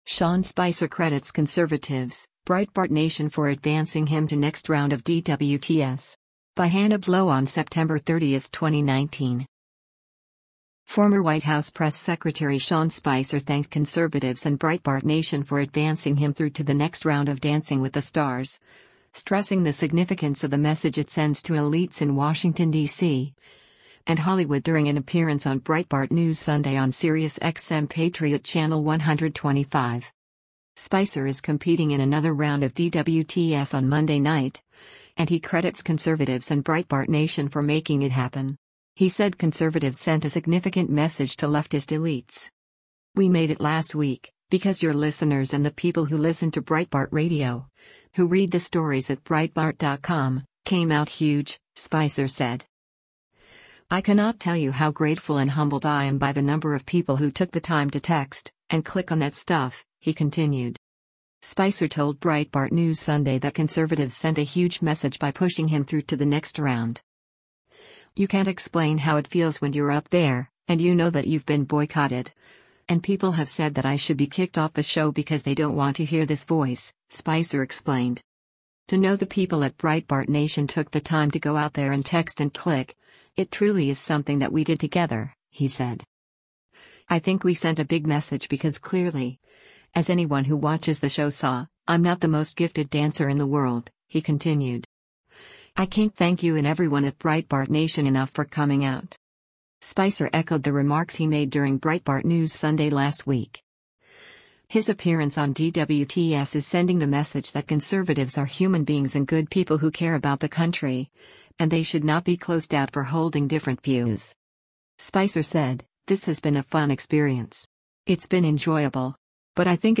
Former White House press secretary Sean Spicer thanked conservatives and Breitbart Nation for advancing him through to the next round of Dancing with the Stars, stressing the significance of the message it sends to elites in Washington, DC, and Hollywood during an appearance on Breitbart News Sunday on SiriusXM Patriot Channel 125.